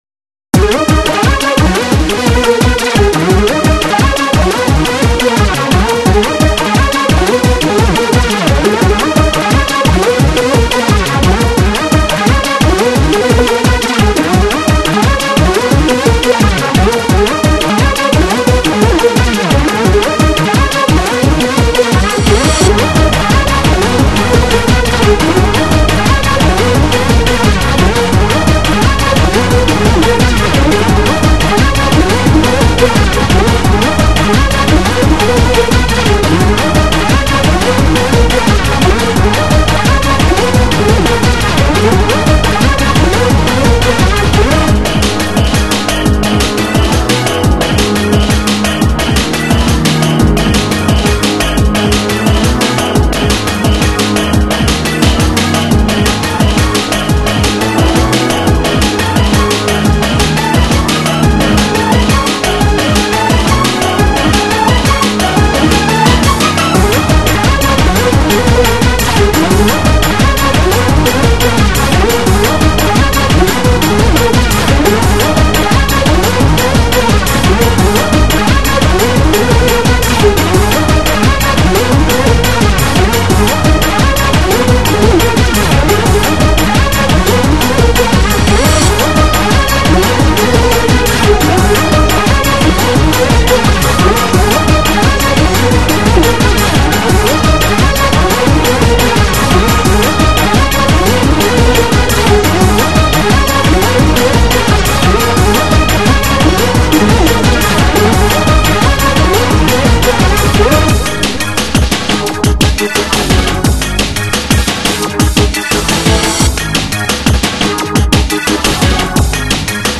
多くの引き出しを意識しつつ、前作 のコンセプト 「ハード そして ダンサブル」 を更に推し進めたオリジナル曲集。